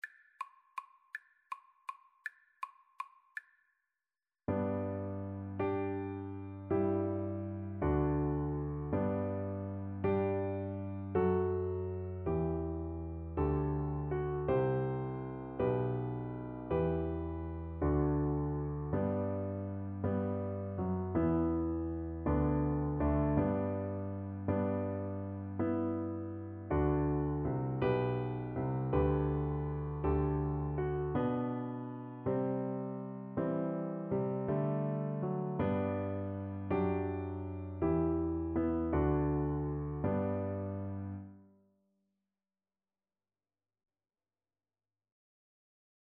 Cello
3/4 (View more 3/4 Music)
G major (Sounding Pitch) (View more G major Music for Cello )
Gentle one in a bar (. = c. 54)
Traditional (View more Traditional Cello Music)